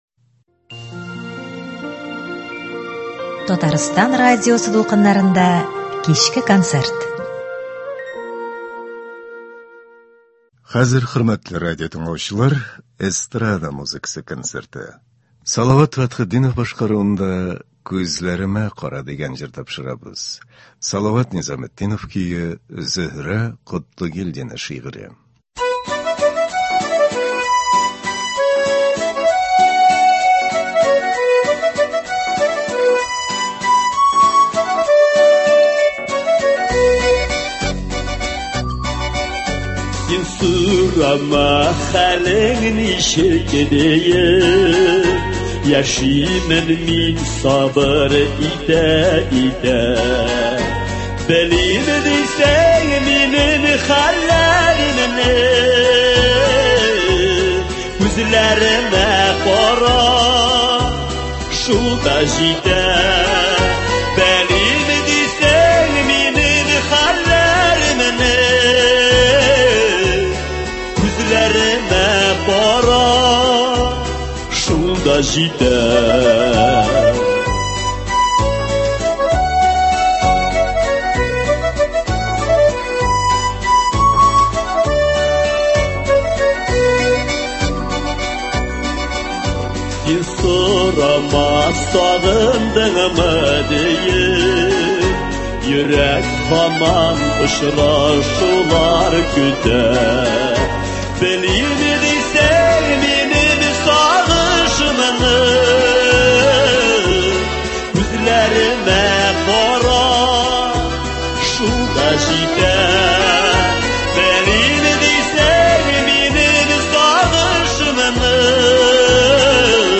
Эстрада музыкасы концерты.